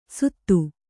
♪ suttu